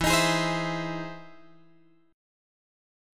E Augmented Major 7th